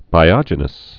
(bī-ŏjə-nəs)